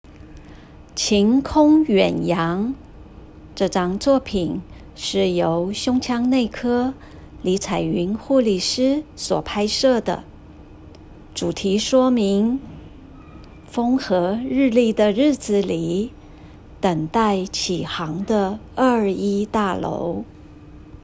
語音導覽-58晴空遠揚.m4a